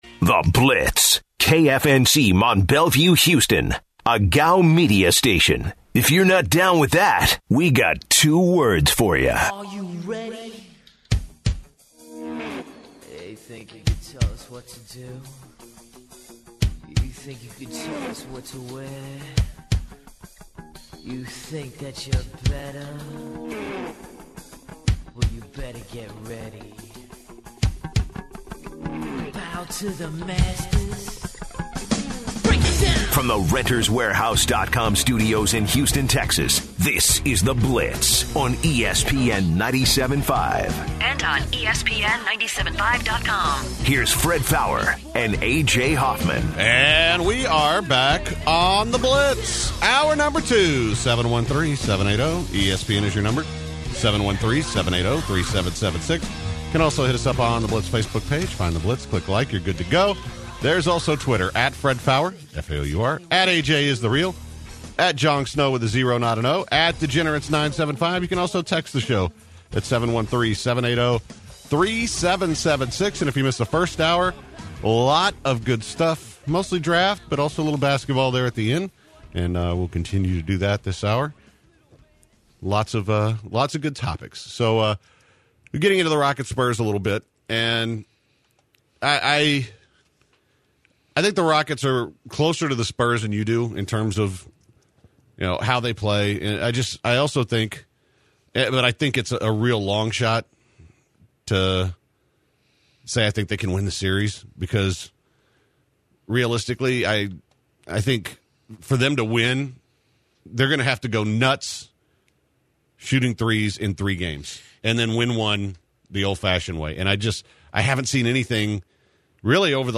In hour two, the guys go in depth of the Rockets-Spurs playoff match-up. Then, the guys take listeners calls about the Rockets, Deshaun Watson, and the Kentucky Derby. Also, the guys weigh in on Deshaun Watson.